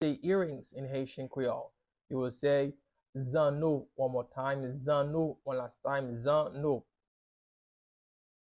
Listen to and watch “Zanno” pronunciation in Haitian Creole by a native Haitian  in the video below:
How-to-say-Earrings-in-Haitian-Creole-Zanno-pronunciation-by-a-Haitian-teacher-1.mp3